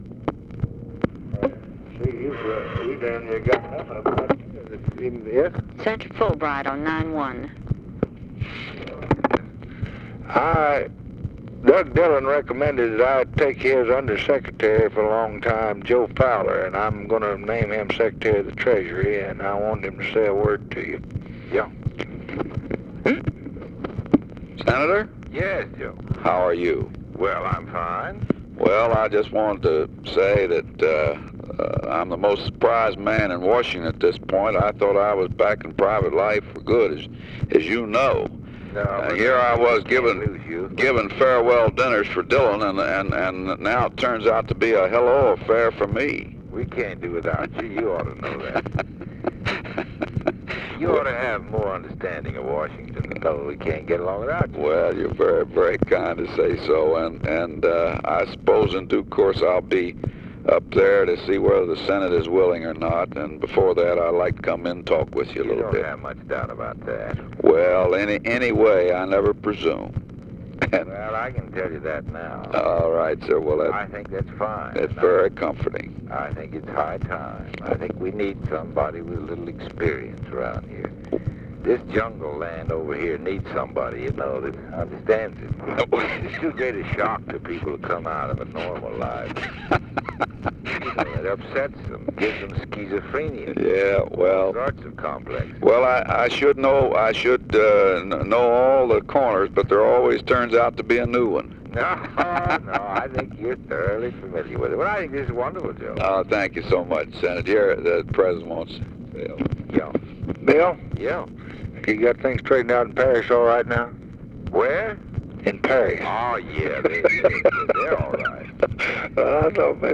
Conversation with WILLIAM FULBRIGHT and HENRY FOWLER, March 18, 1965
Secret White House Tapes